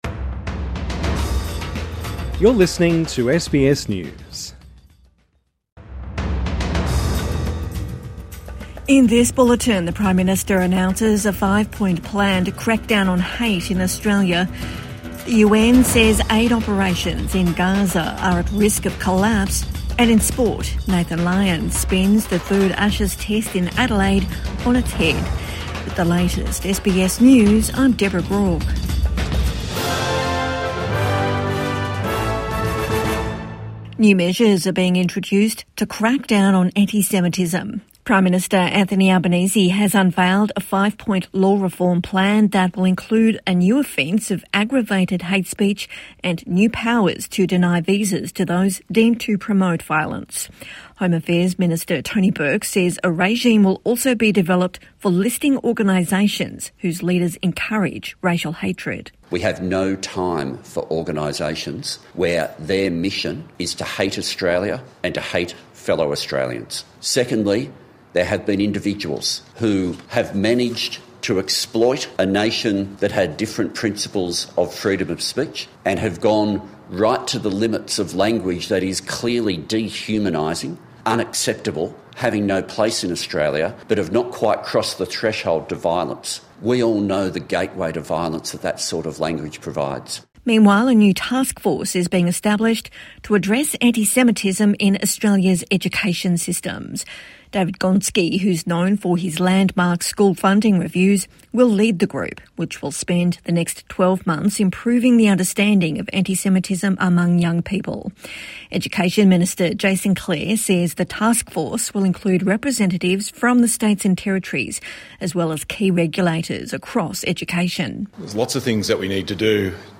PM unveils five point plan to punish antisemitism | Evening News Bulletin 18 December 2025